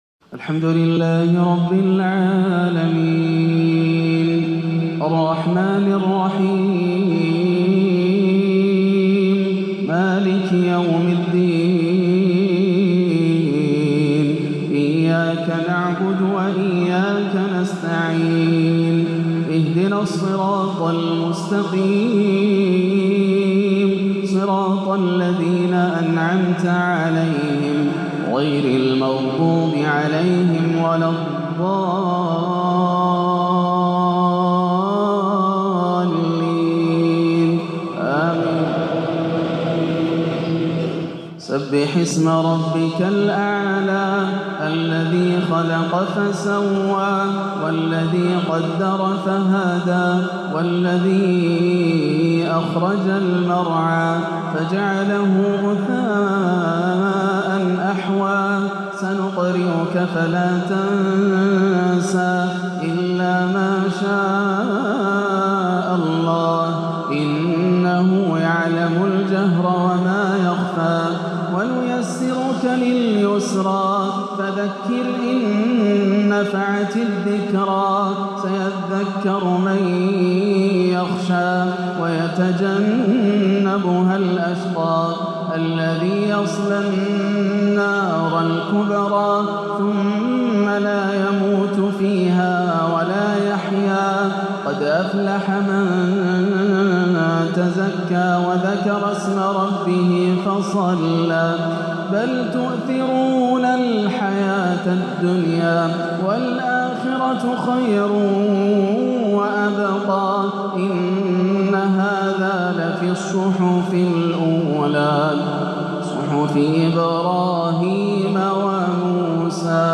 صلاة الجمعة 7-4-1438هـ سورتي الأعلى و الغاشية > عام 1438 > الفروض - تلاوات ياسر الدوسري